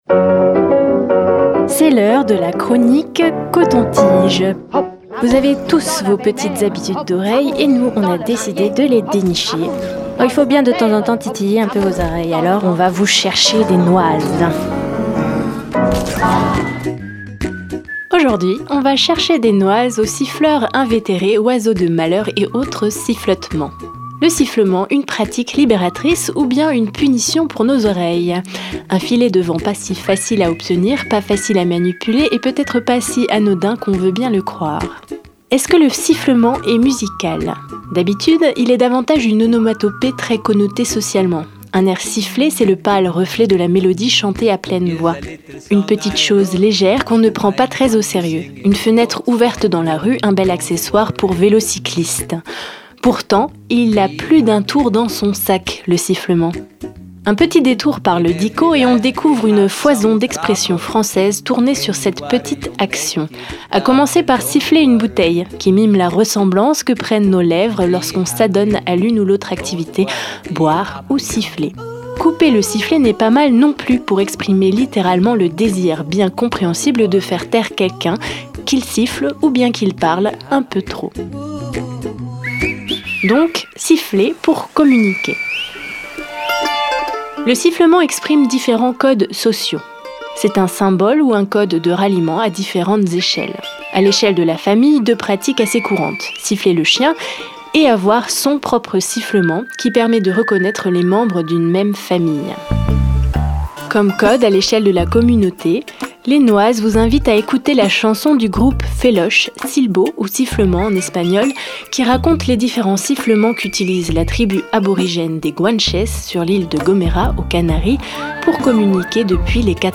On est allé chercher des noises aux siffleurs de tous poils et de toutes plumes : gazouilleurs invétérés, toboggans flegmatiques, trilles énervés, chatouilleurs du dimanche. Petite analyse au cas où vous auriez les oreilles qui sifflent... comment cette petite chose toute simple qu'est le sifflotement fait-elle musique?